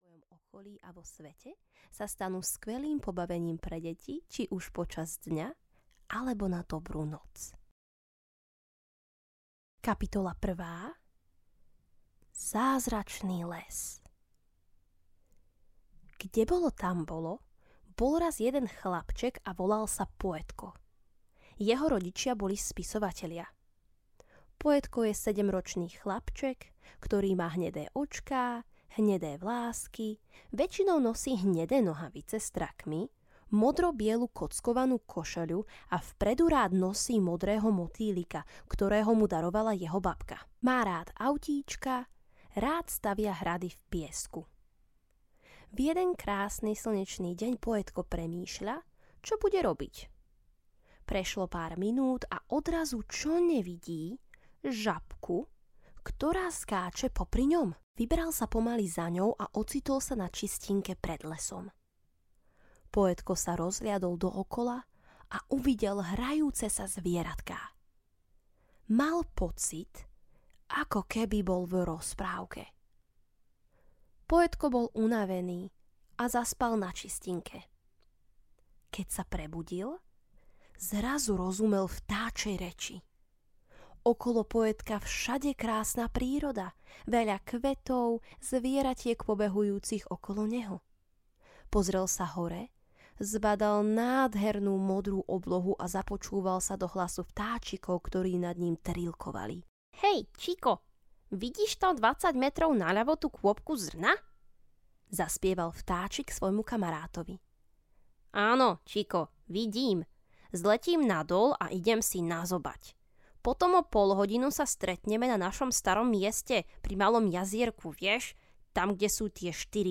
Audio knihaPoetkove dobrodružstvá
Ukázka z knihy